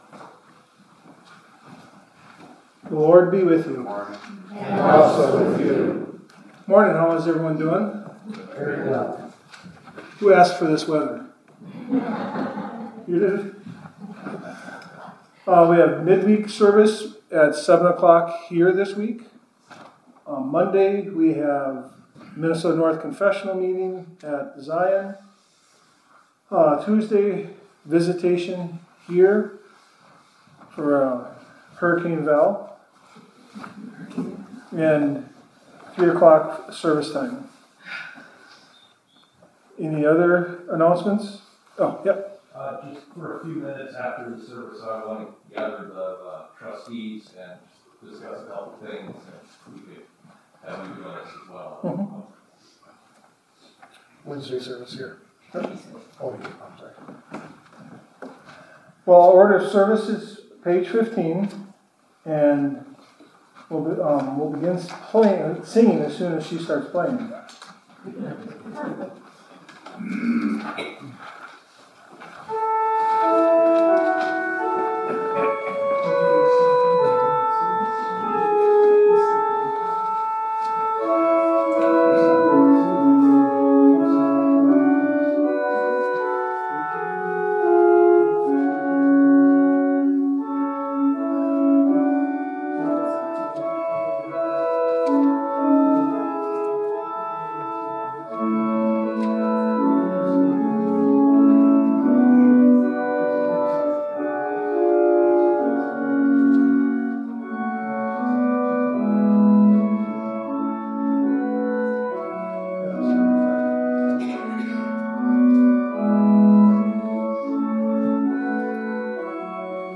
Immanuel-Worship-16-Mar-25.mp3